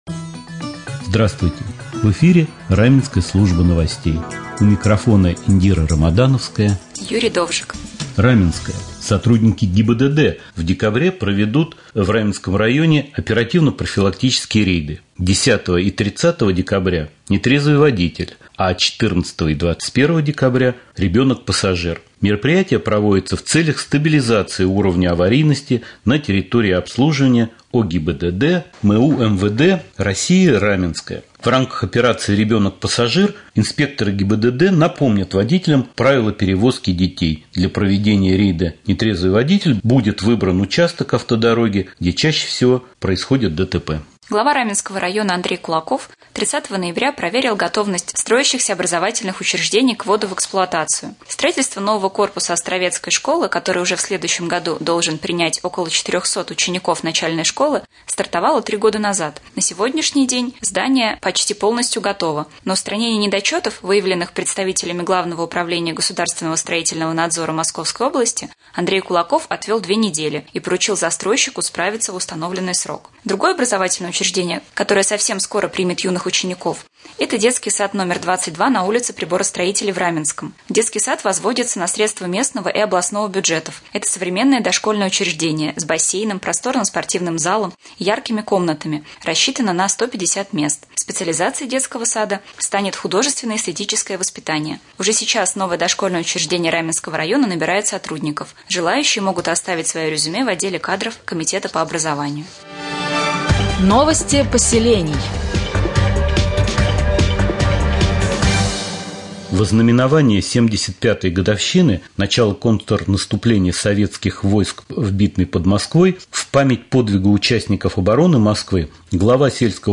1. Новости